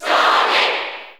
Sonic (SSBU) Category: Crowd cheers (SSBU) You cannot overwrite this file.
Sonic_Cheer_English_SSBU.ogg